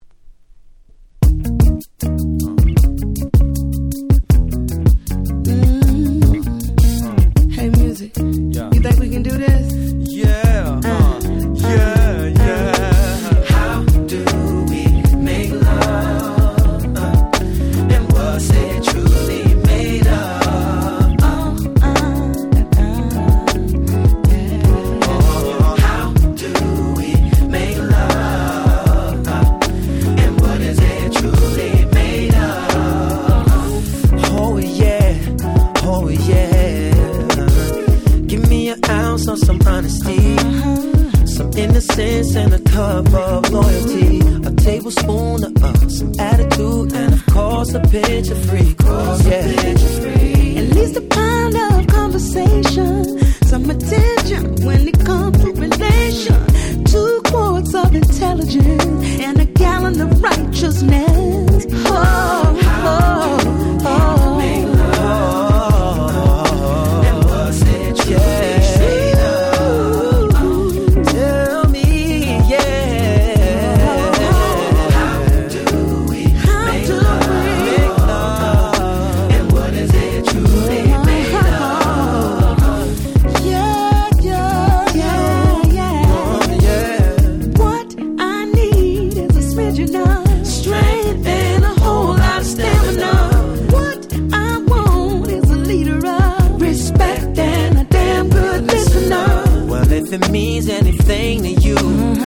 01' Very Nice R&B / Neo Soul !!